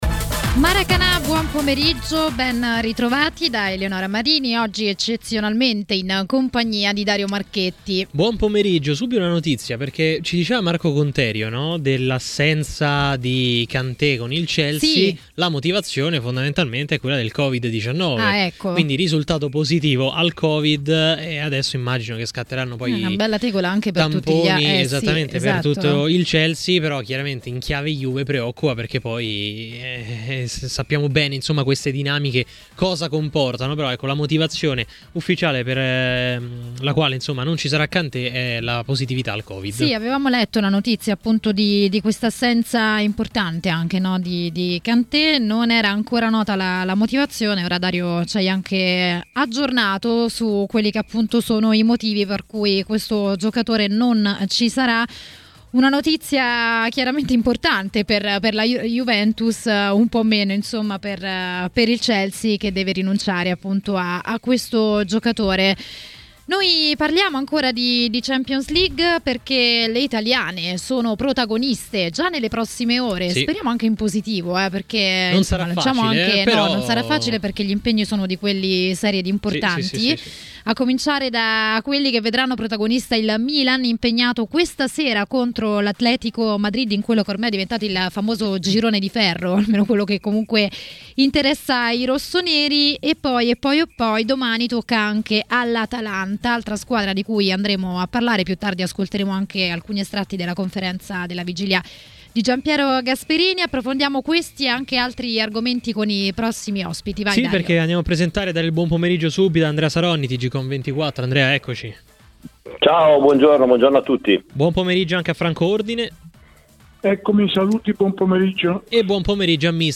A parlare di Champions a Maracanà, nel pomeriggio di TMW Radio, è stato mister Gianni Di Marzio.